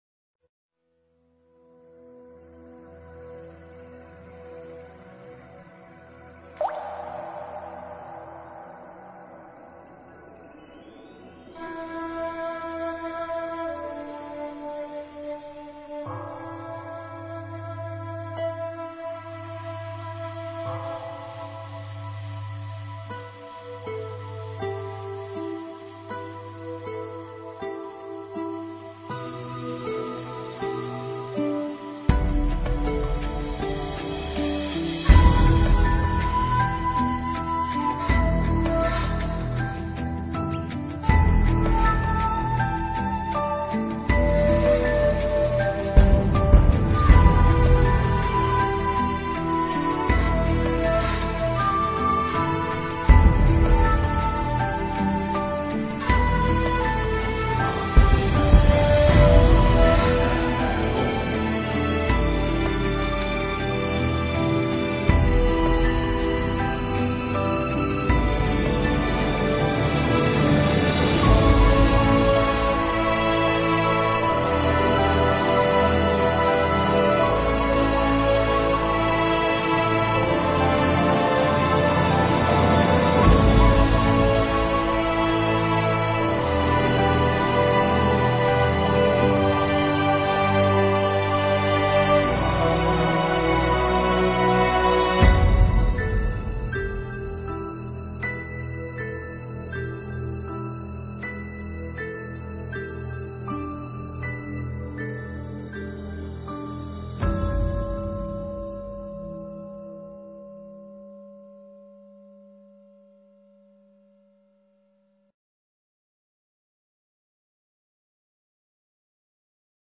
少林寺(下)--有声佛书